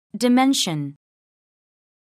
미국[diménʃən]